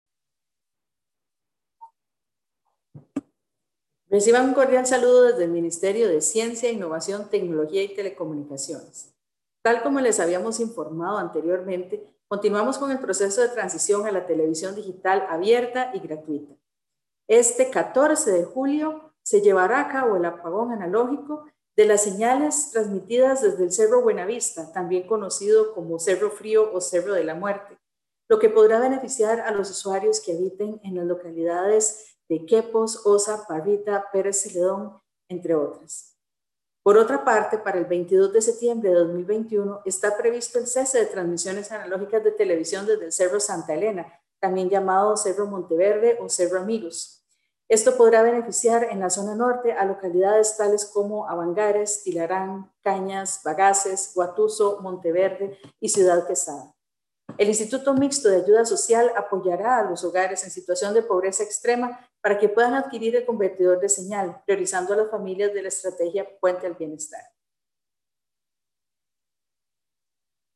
Declaraciones de la ministra Paola Vega Castillo sobre apagón de señales analógicas de Televisión en el Cerro Buenavista